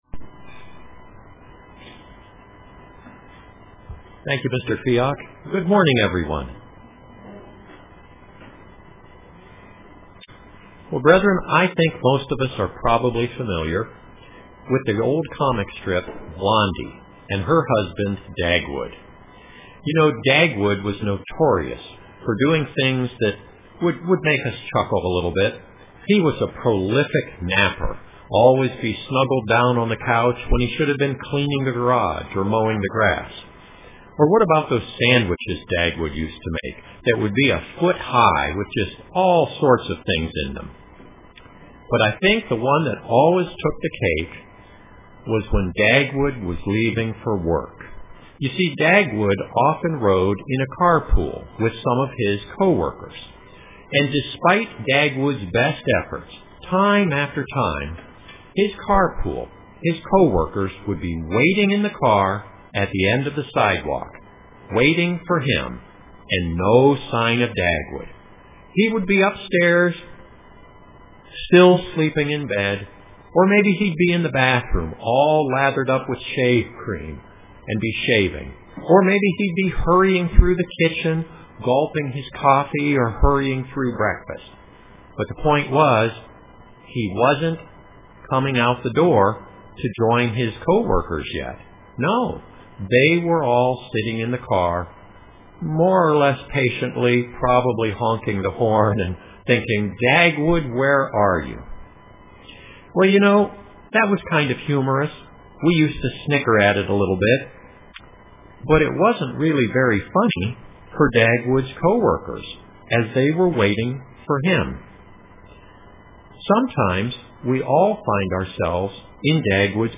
Print Is God Waiting for Me UCG Sermon Studying the bible?